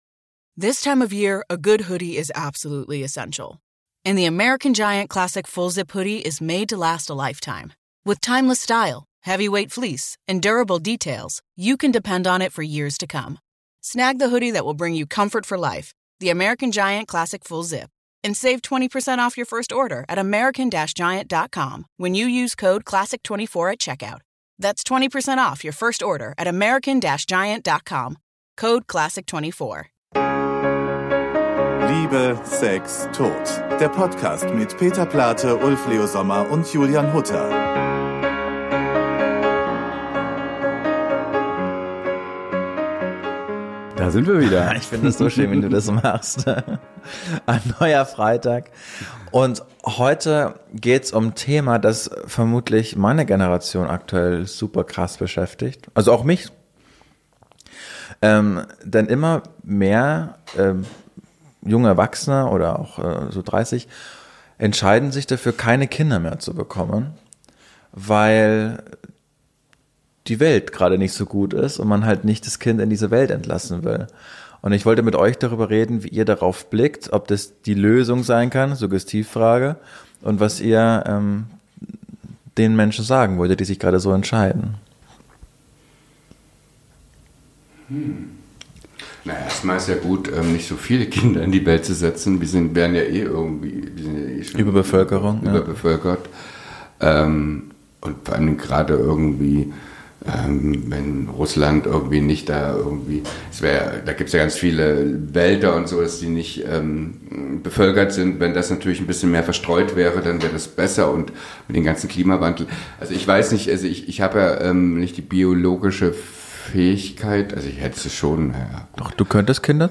Ach, ja: Und dieses Gespräch könnt ihr auch als Video verfolgen.